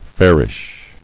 [fair·ish]